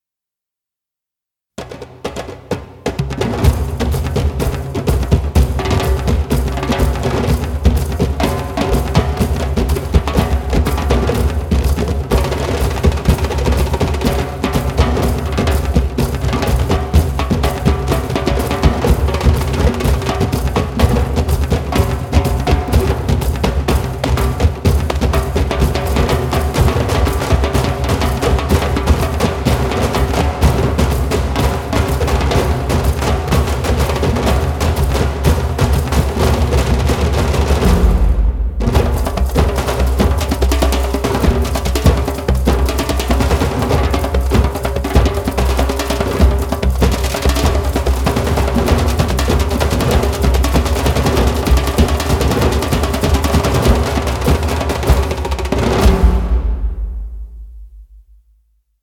非洲打击乐 Strezov Sampling Djembe X3M KONTAKT-音频fun
将我们的采样理念应用于Djembe分支的各种乐器，产生了一些非常有机和新鲜的声音结果，与我们的X3M打击乐引擎相结合，演奏起来简直是一种乐趣。
录制在Sofia Session Studio进行，我们还录制了以前的X3M库，以便进行平滑和轻松的分层。
除此之外，引擎还允许您调整单个区域，以及具有各种效果和麦克风位置的整个映射合奏补丁。